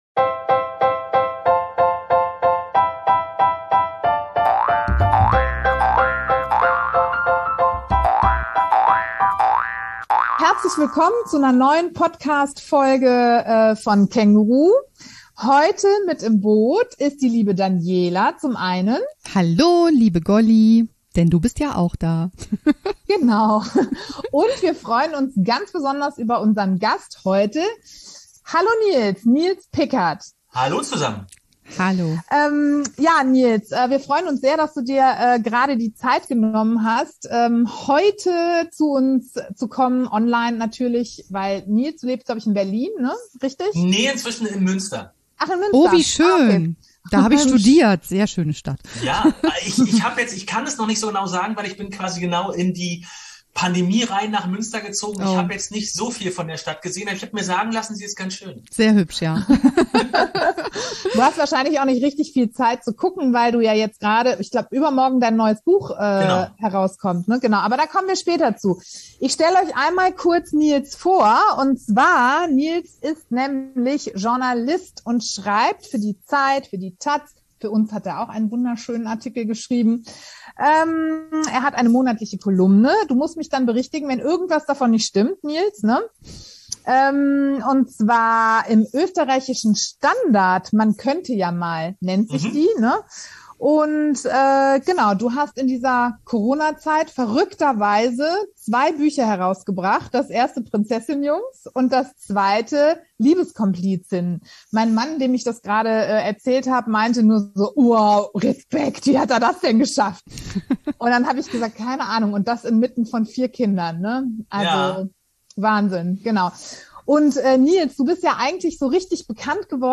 Im KÄNGURU-Podcast sprechen wir mit Expert:innen über Dinge, die Familien beschäftigen.